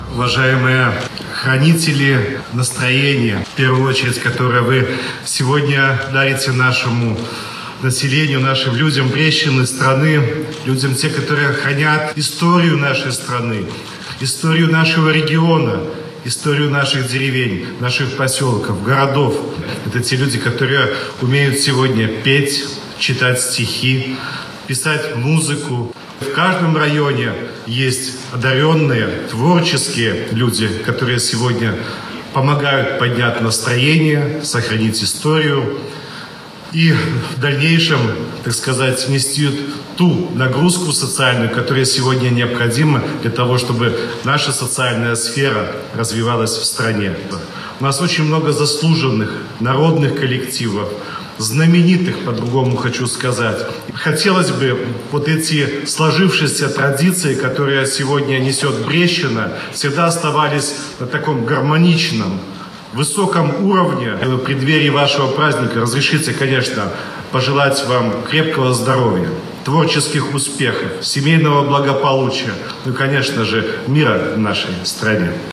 Накануне в Барановичском городском Доме культуры состоялось торжество, на которое были приглашены лучшие представители творческих профессий области. Собравшихся приветствовал председатель Брестского облисполкома Юрий Шулейко. В своём выступлении губернатор поблагодарил за работу и отметил особое отношение к людям, которые сохраняют и умножают культурное, историческое наследие нашей республики.